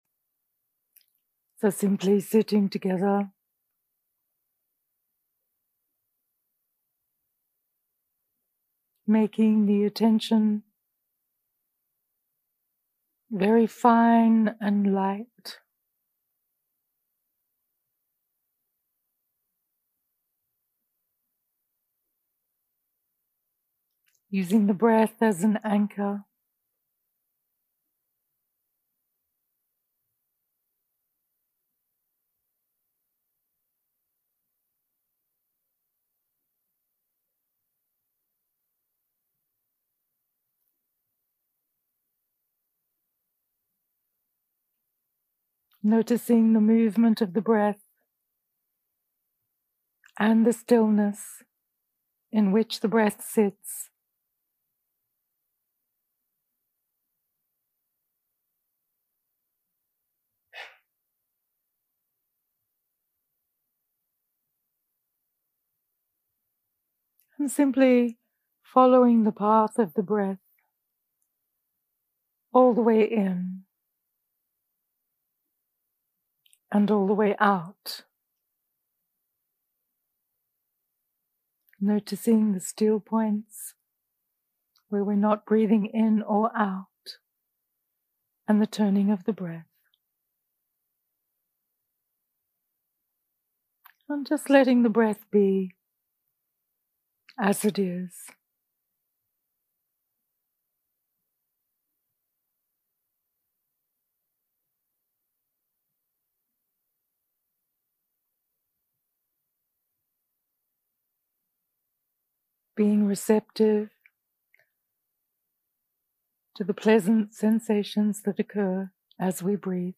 יום 1 – הקלטה 1 – ערב – מדיטציה מונחית והנחיות למדיטציה – תשומת לב לנשימה 0:00 0:00 סוג ההקלטה: Dharma type: Guided meditation שפת ההקלטה: Dharma talk language: English